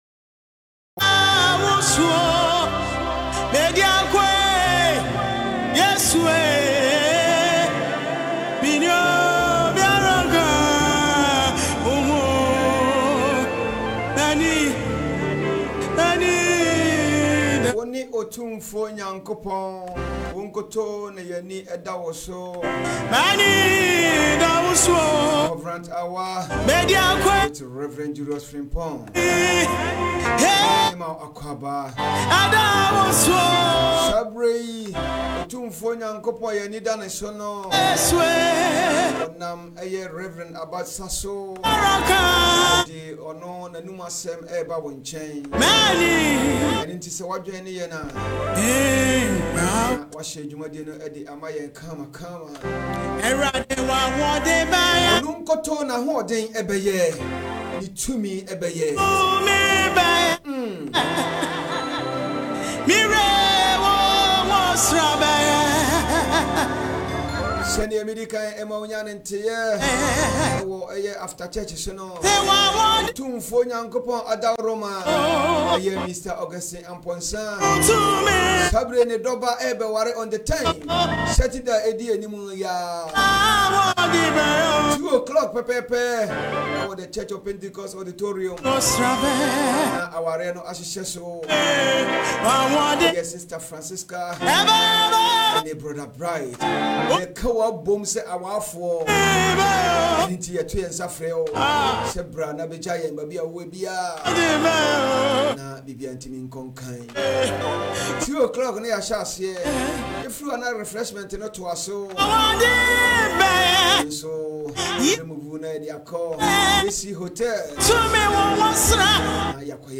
Religió